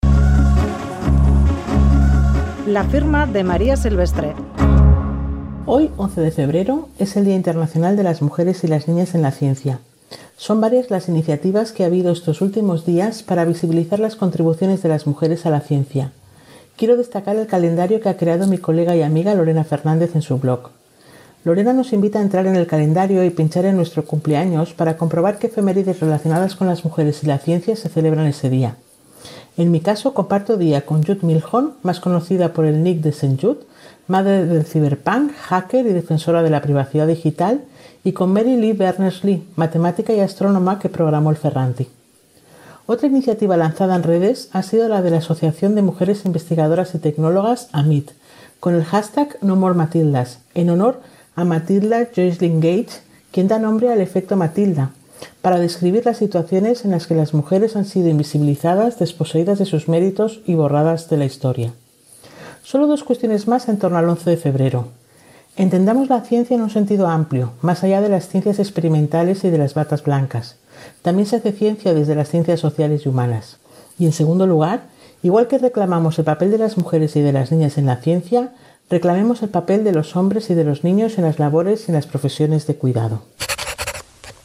Radio Euskadi LA FIRMA El efecto Matilda Publicado: 11/02/2021 11:58 (UTC+1) Última actualización: 11/02/2021 11:58 (UTC+1) Columna de opinión en "Boulevard" de Radio Euskadi.